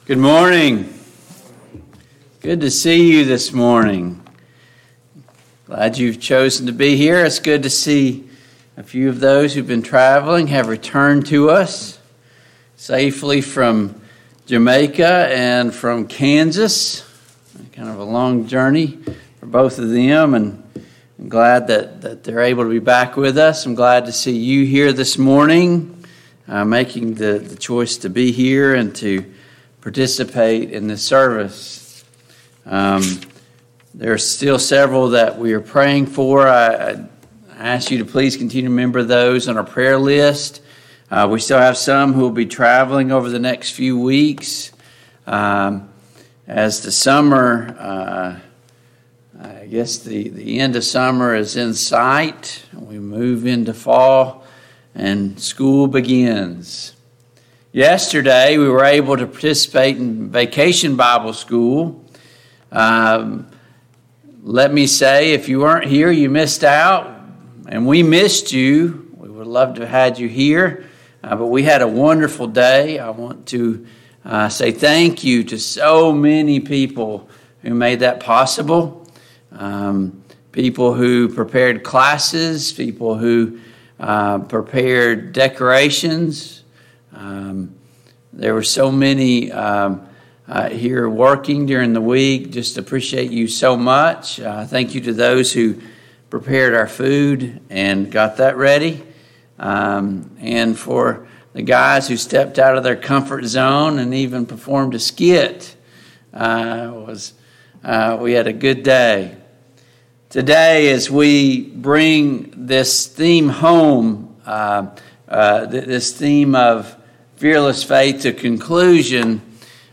2022 VBS Adult Lessons Passage: Acts 17 Service Type: AM Worship « 10.